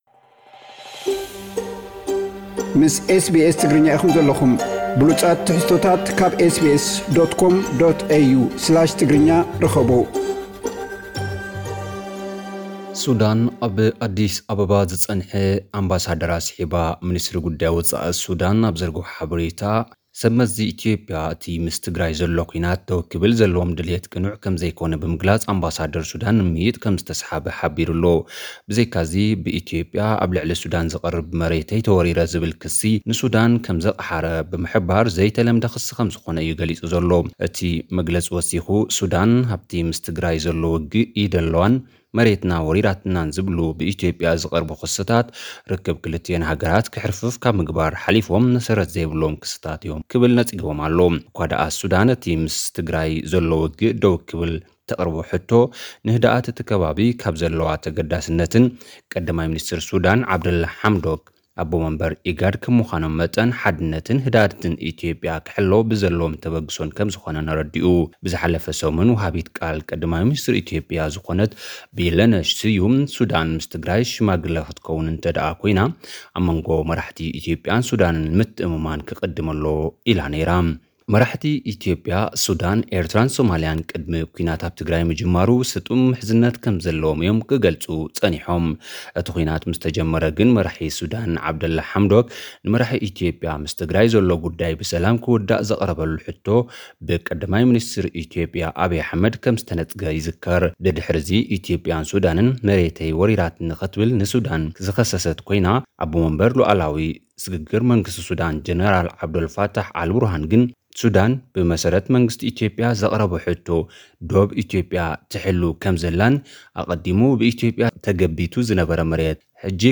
ሓጸርቲ ጸብጻባት፥ ሱዳን ኣብ ኢትዮጵያ ዝነበረ ኣምባሳደራ ንምምይይጥ ስሒባ። ዋጋ ዶላር ኣብ ኢትዮጵያ ብ5 ዕጽፊ ወሲኹ ተባሂሉ። ትካላት ጥዕና ኢትዮጵያ ብውጉኣት ኩናት ከም ዘዕለቕለቓ ተገሊጹ። ኣብ ምዕራብ ትግራይ መቕተልቲን ምድጓንን ተጋሩ ገዲዱ ከም ዘሎ ተገሊጹ። ዝብሉ ኣርእስታት ዝሓዘ ሓጸርቲ ጸብጻባት ልኡኽና።